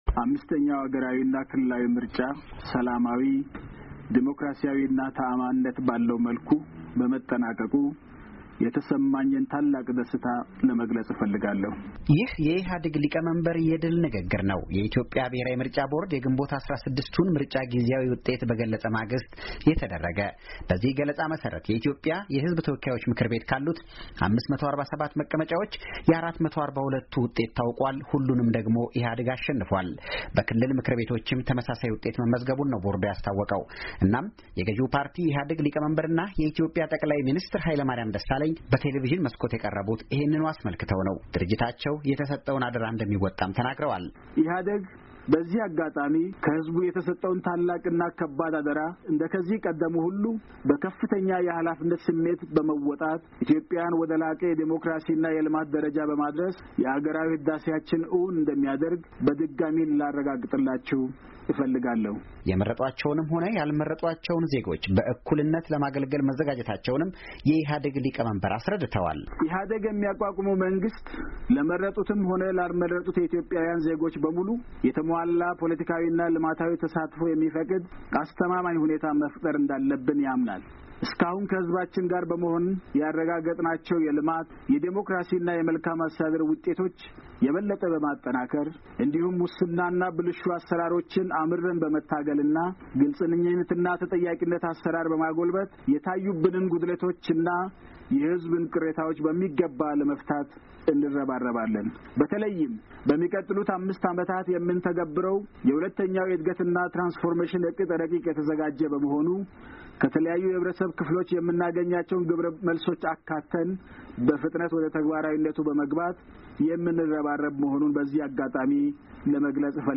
PM Hailemariam Desalegne Speech